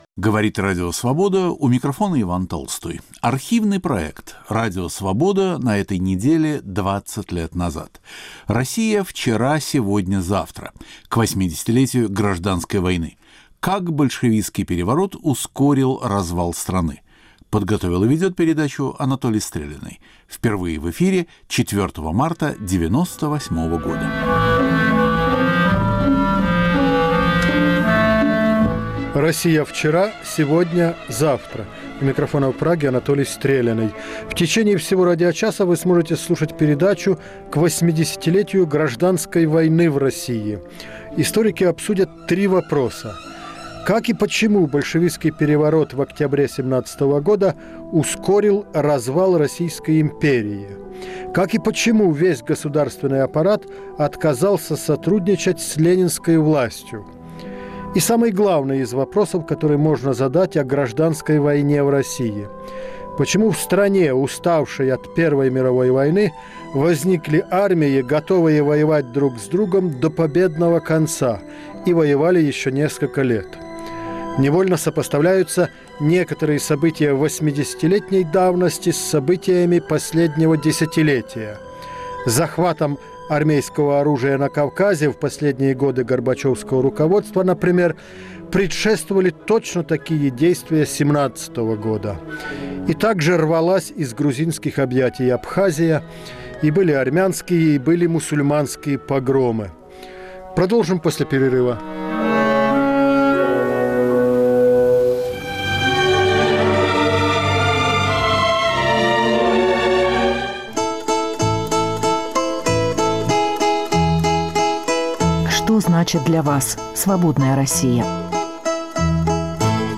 Подготовил и ведет передачу Анатолий Стреляный.
Как и почему государственный аппарат отказался сотрудничать с ленинской властью? Как и почему возникли Белая и Красная армии? Обсуждают историки.